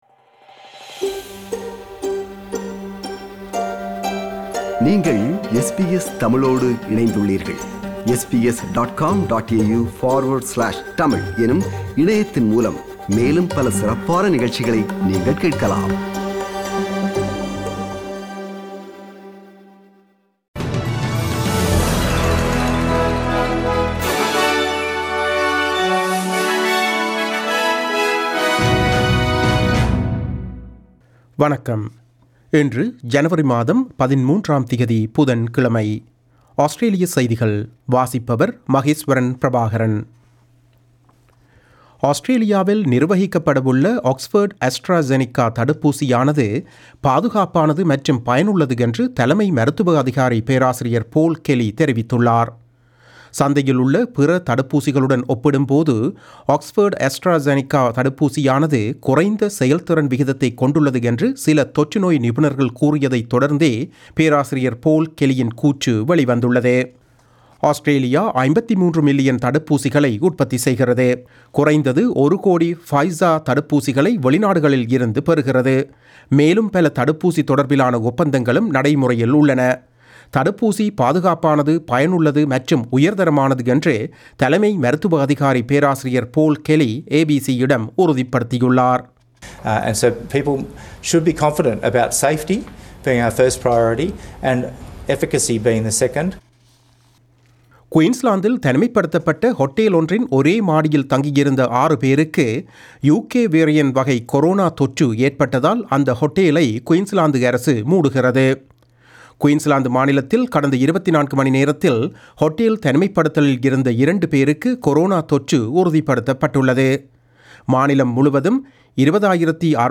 Australian news bulletin for Wednesday 13 January 2021.